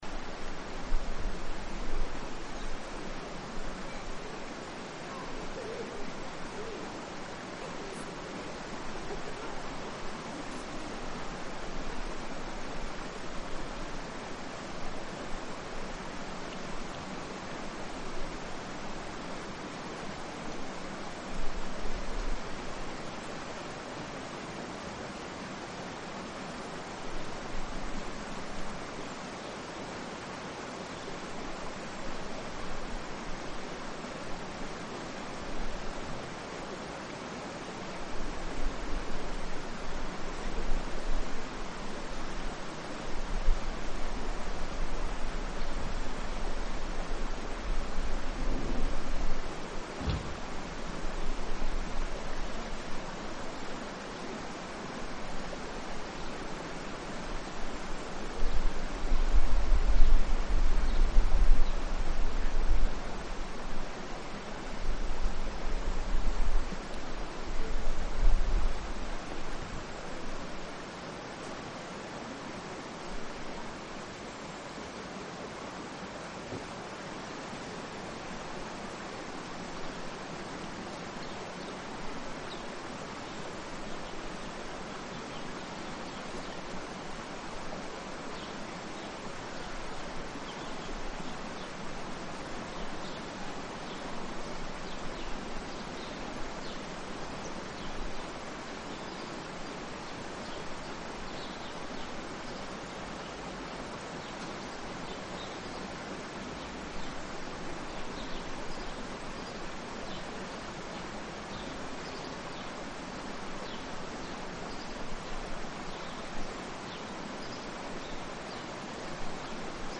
sound of the water , sound of animals , water , birds , crowd
El sonido del agua, de los pájaros y del bullicio callejero debieron ser elementos característicos de su paisajes sonoro.
Soundscapes in Cabrera's bridge.